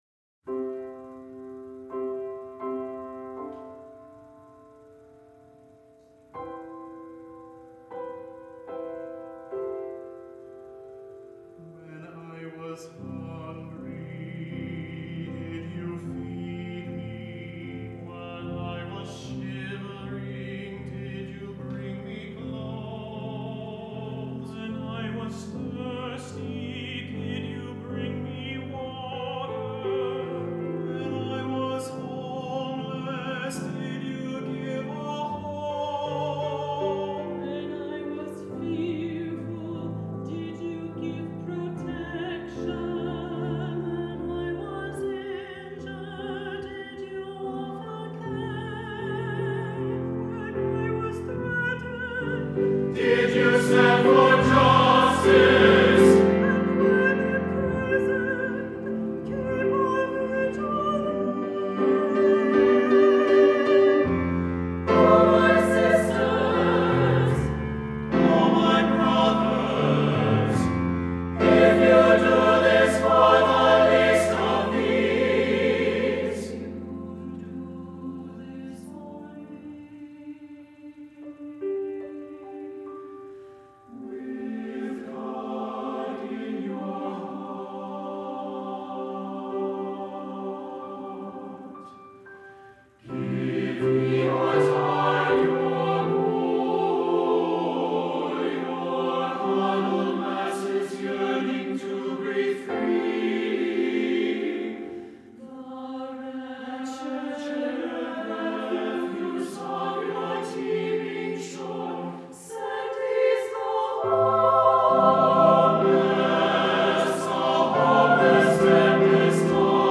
for SATB Chorus and Piano (2018)